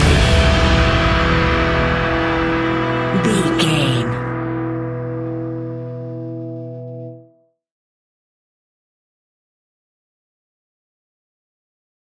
Cello Horror Stab Part 3.
Aeolian/Minor
scary
tension
ominous
dark
suspense
eerie
strings
drums
percussion
viola